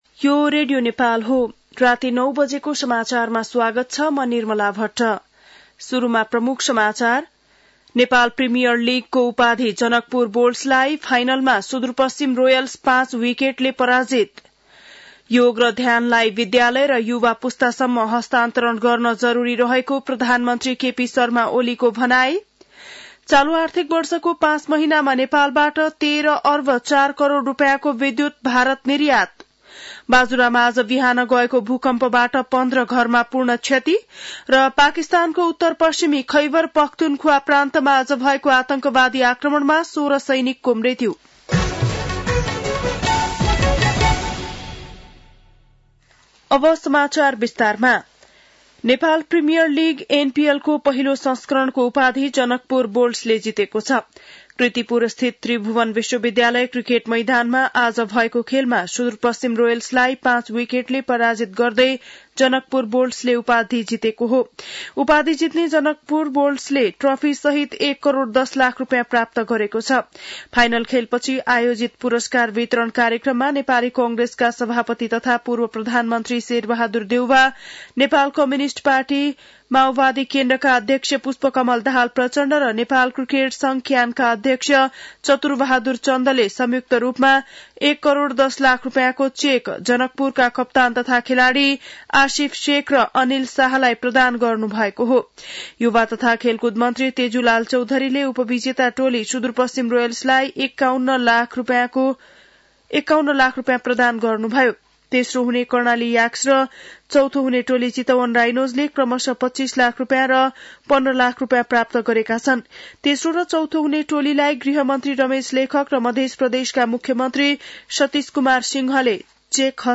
बेलुकी ९ बजेको नेपाली समाचार : ७ पुष , २०८१
9-PM-Nepali-News-9-6.mp3